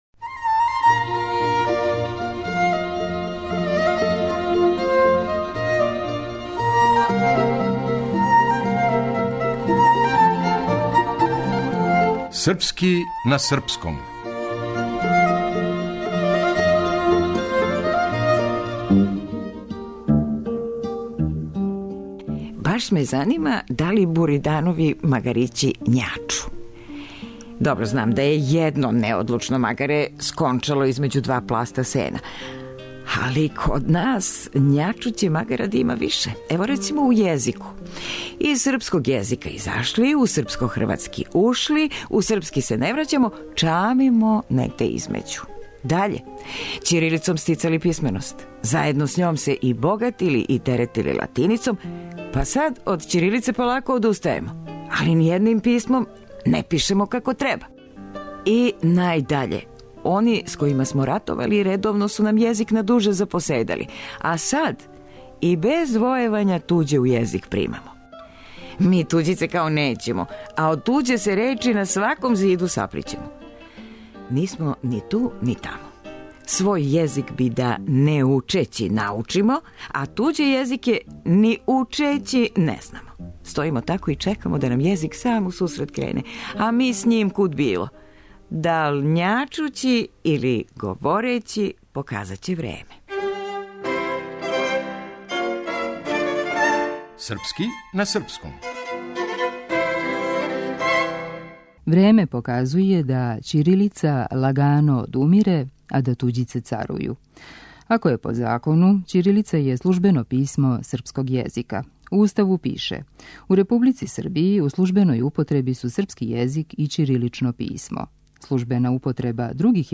Драмски уметник: